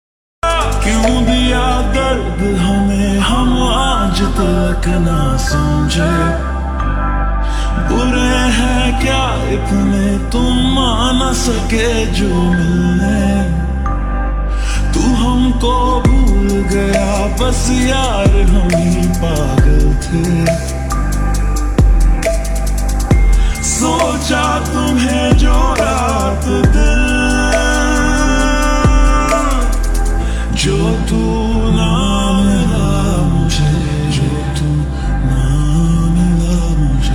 ( Slowed + Reverb)